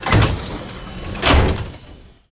ElevatorClose3.ogg